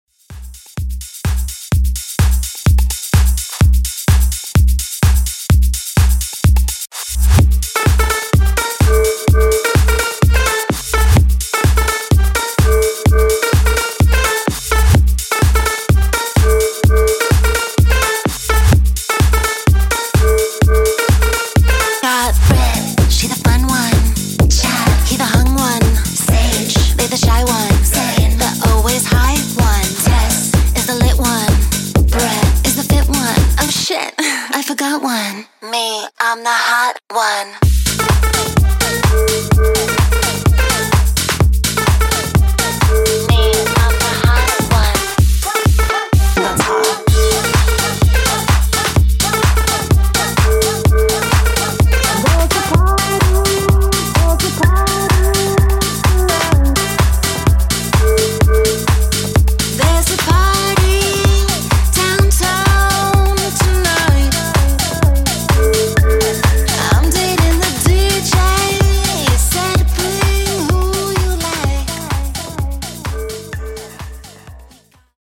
Club House)Date Added